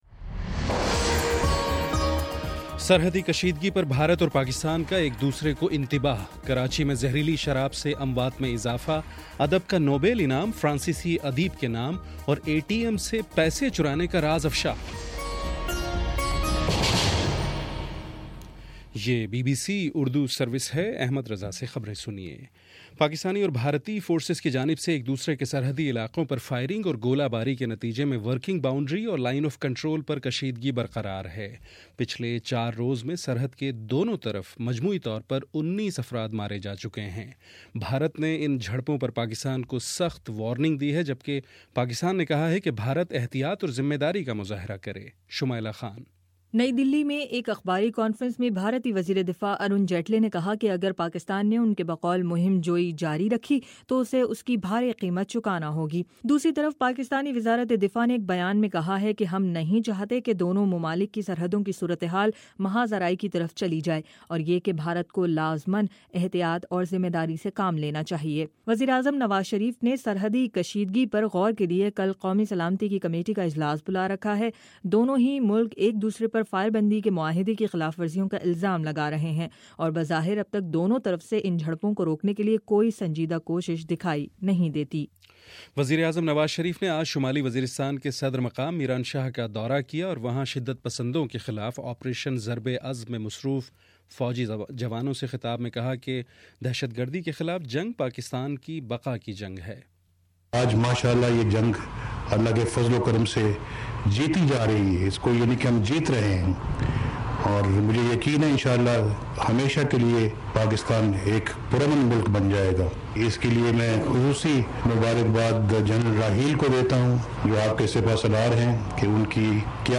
اکتوبر09 : شام سات بجے کا نیوز بُلیٹن